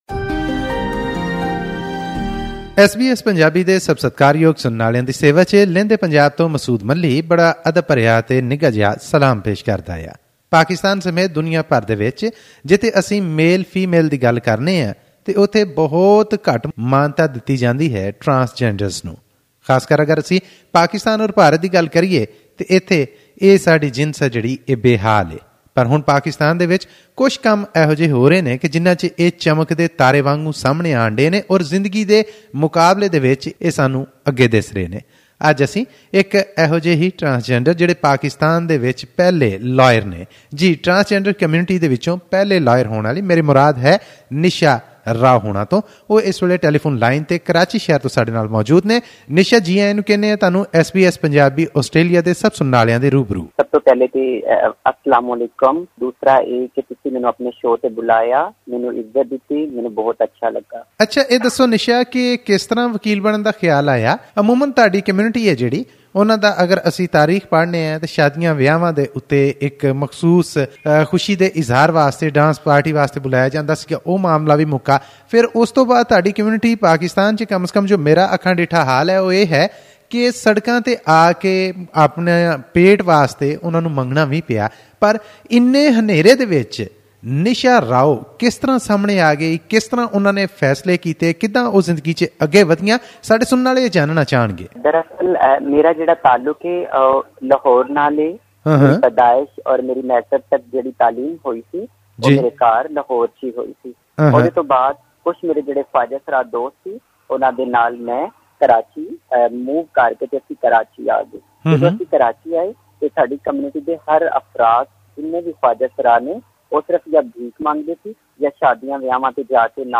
Click the audio icon on the photo above to listen to the full interview in Punjabi.